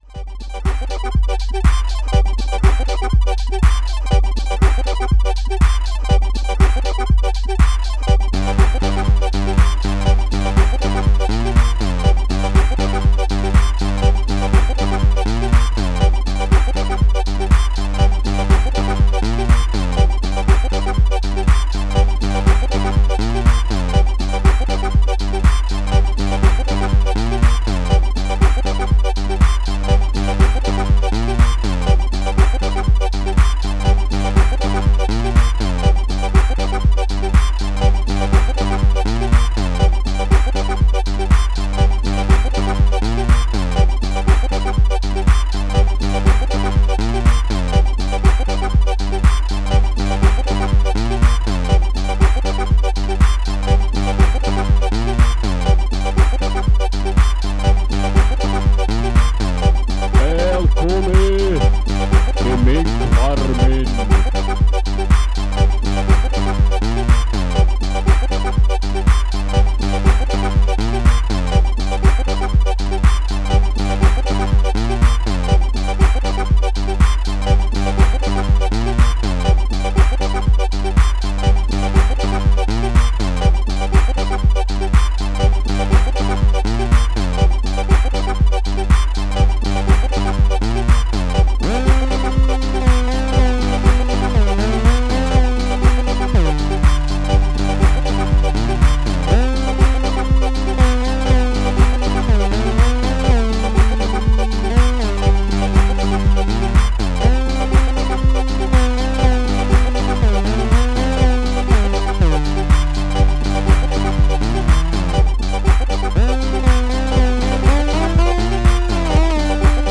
Int kunna di spel i takt naa heldär.